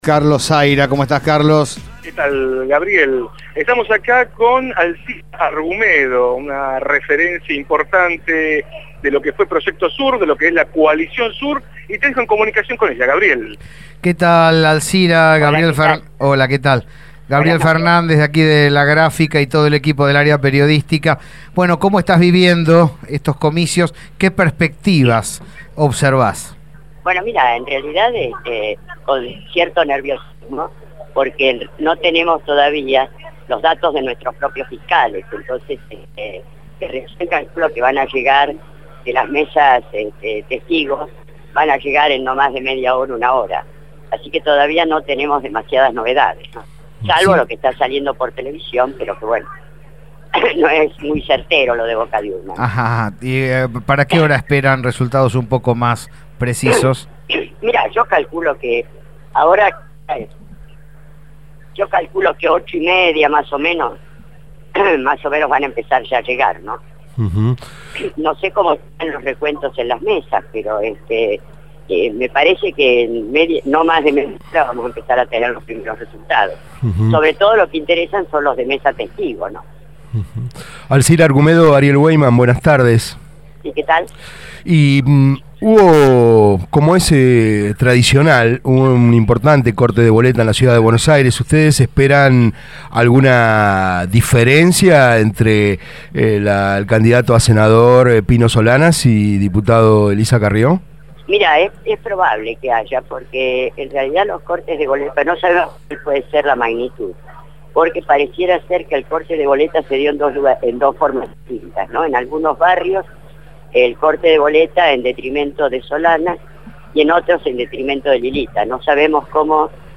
Desde el Paradise Rouge, Petit Hotel en el Barrio de Palermo, centro de campaña de Coalición Sur en UNEN, se entrevistó a Alcira Argumedo, Diputada Nacional por Proyecto Sur.